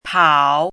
怎么读
pǎo páo
pao3.mp3